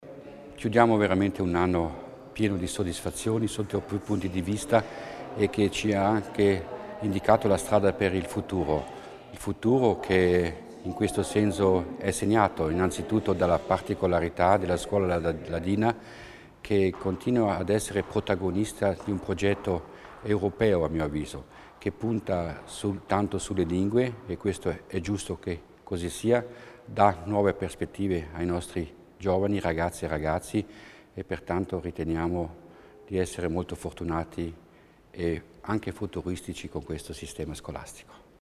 L'Assessore Sabina Kasslatter Mur spiega l'importanza di informare i giovani riguardo le opportunità a loro disposizione